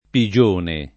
pigione [ pi J1 ne ] s. f.